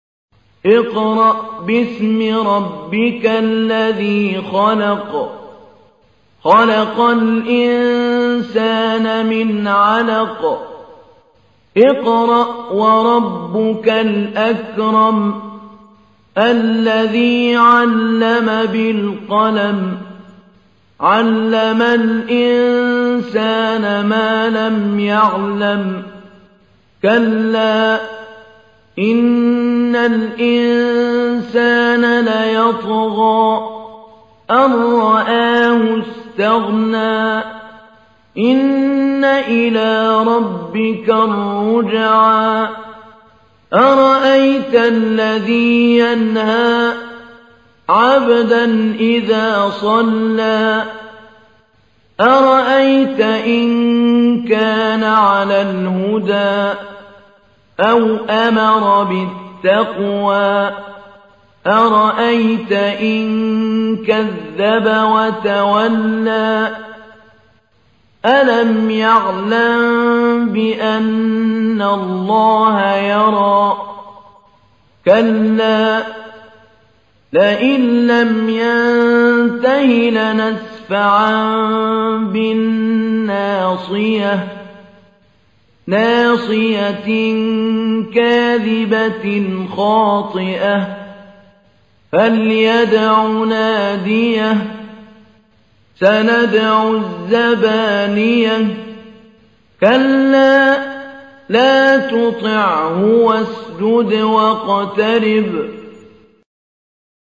تحميل : 96. سورة العلق / القارئ محمود علي البنا / القرآن الكريم / موقع يا حسين